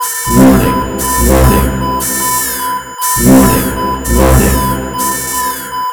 Damage.wav